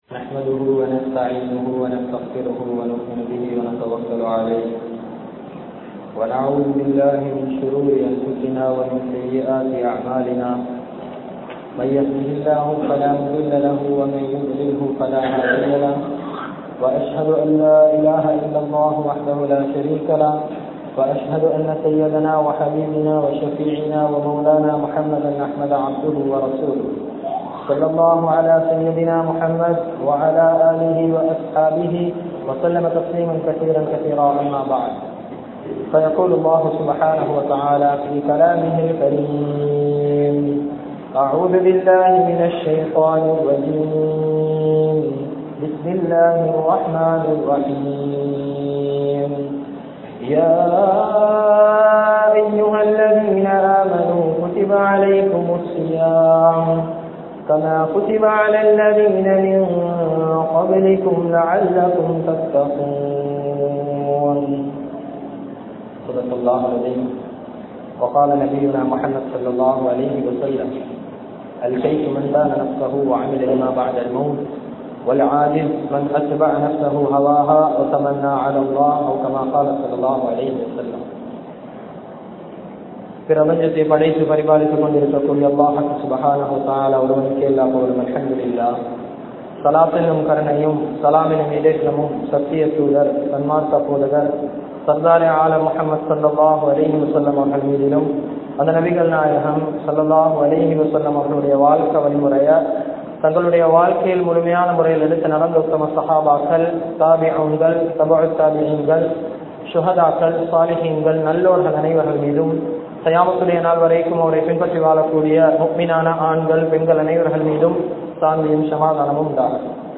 Lailathul Kathrilum Mannikka Padaatha 4 Koottam (ரமழானிலும் மன்னிக்கப்படாத 4 கூட்டம்) | Audio Bayans | All Ceylon Muslim Youth Community | Addalaichenai
Humaidiya Jumua Masjidh